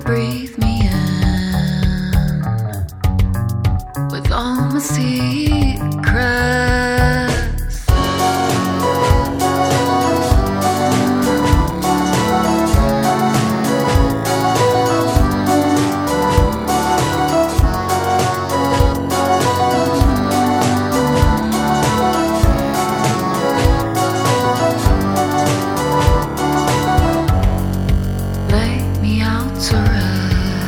De retour en formation trio